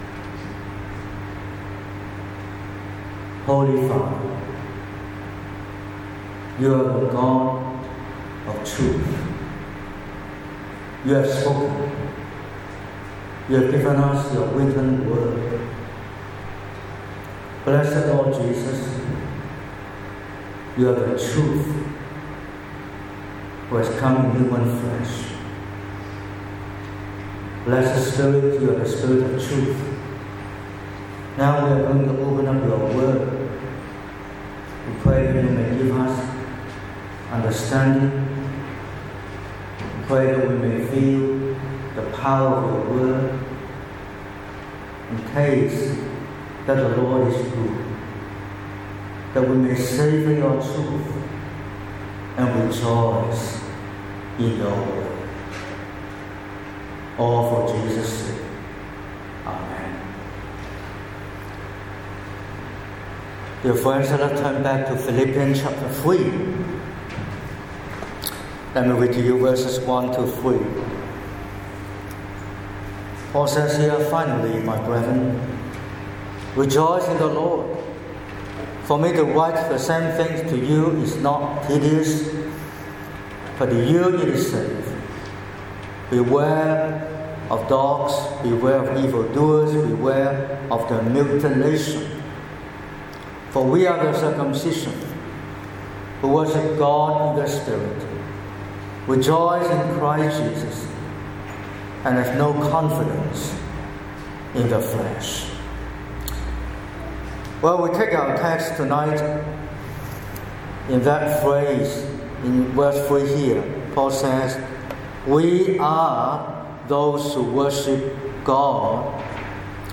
15/03/2026 – Evening Service: Worship by the Spirit